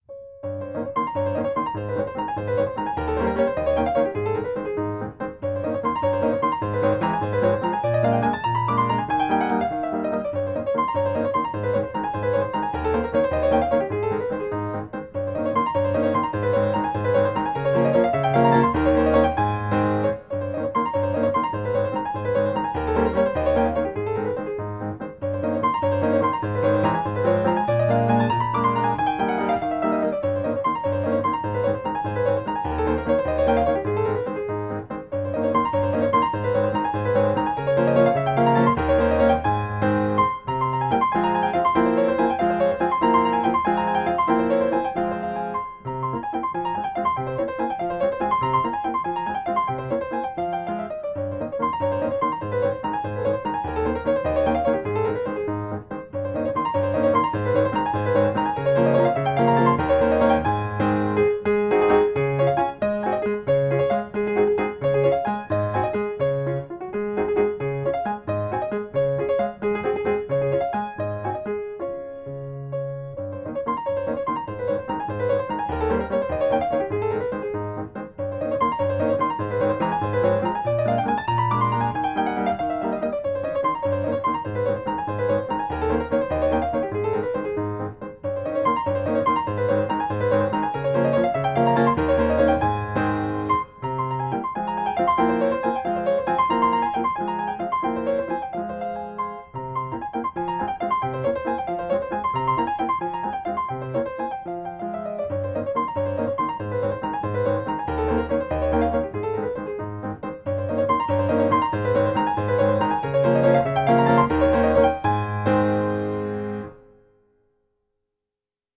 No parts available for this pieces as it is for solo piano.
Piano  (View more Intermediate Piano Music)
Classical (View more Classical Piano Music)